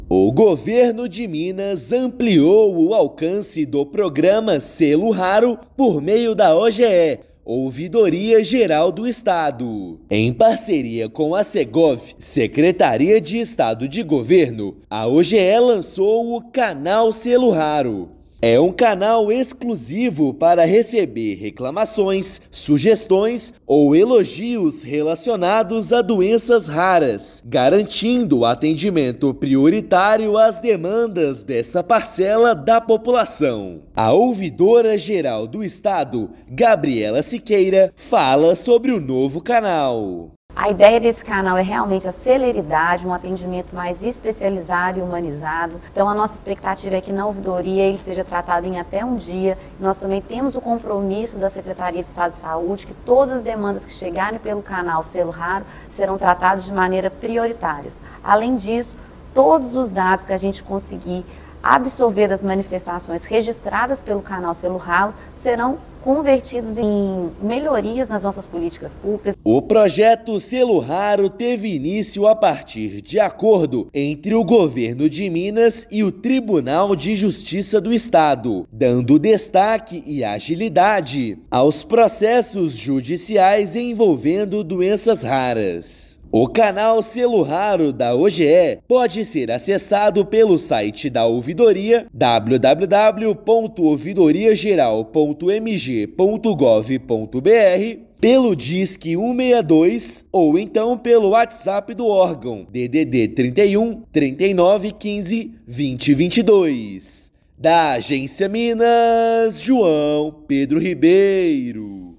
Política pública que teve início como um destaque dado aos processos judiciais envolvendo doentes raros agora será estendida a manifestações. Ouça matéria de rádio.